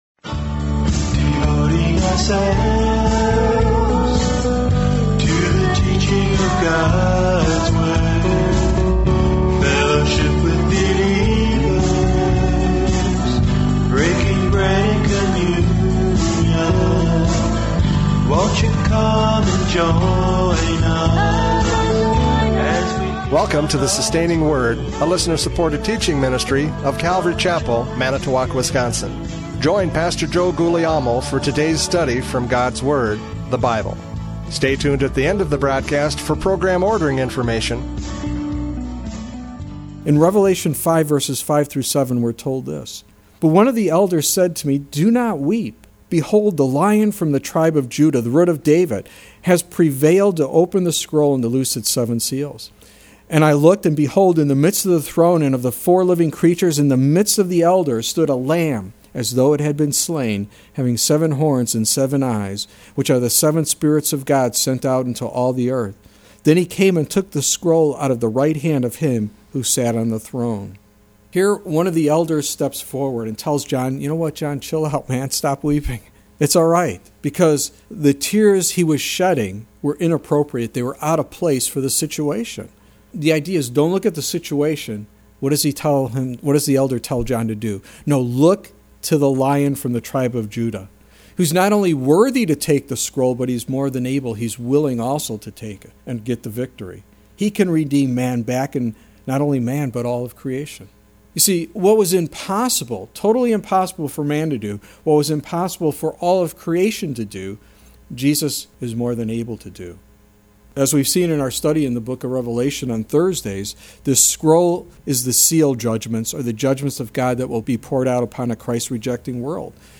John 5:22-30 Service Type: Radio Programs « John 5:22-30 Equality to Judge!